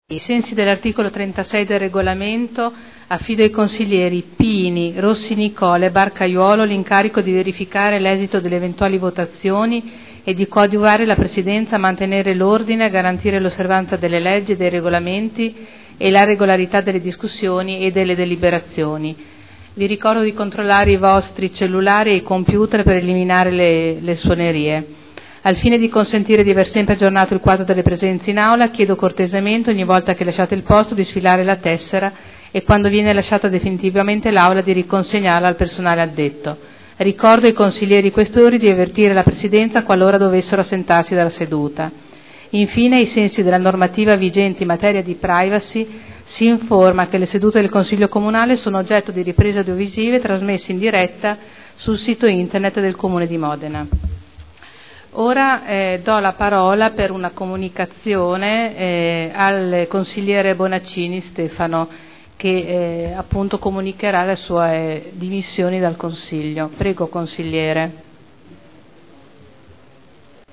Apertura del Consiglio Comunale